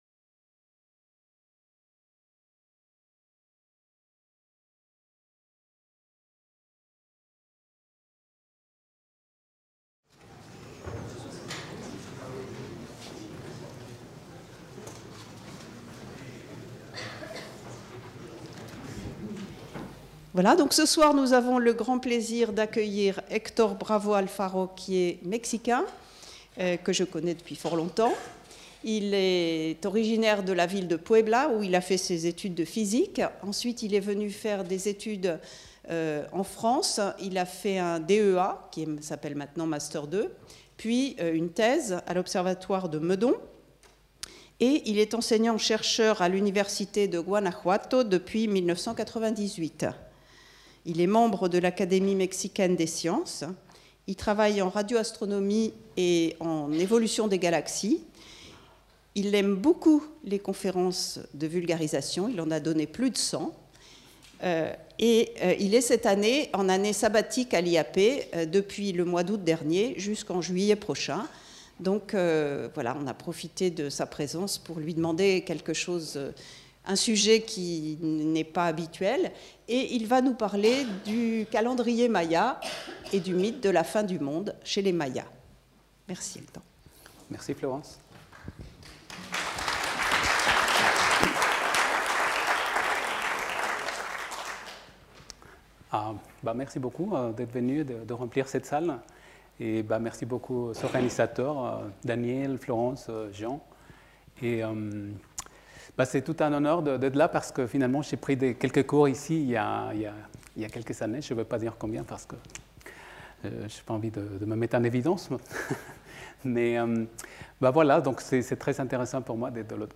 Cette conférence est proposée comme un voyage dans le monde ancien des Mayas, en visitant principalement leur façon - très intéressante - d'approcher le concept du temps.